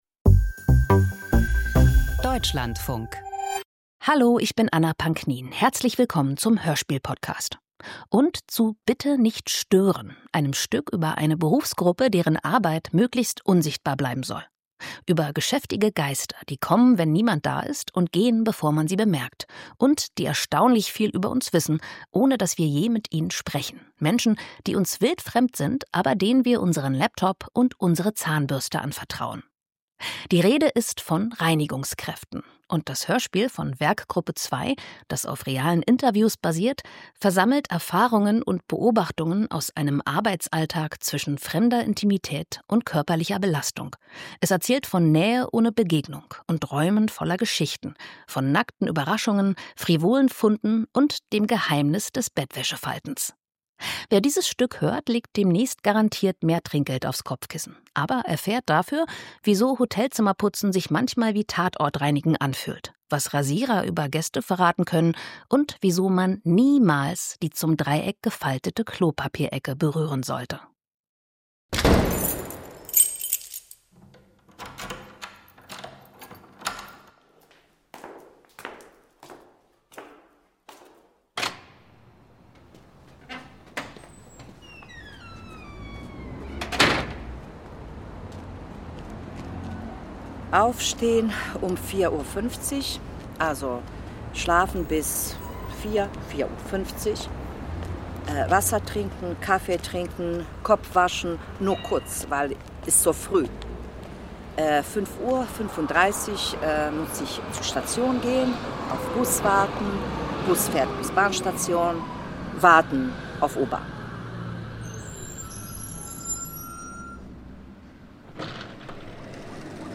Doku-Hörspiel